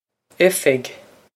effig
This is an approximate phonetic pronunciation of the phrase.